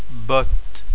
ɔ* o ouvert botte
botte.wav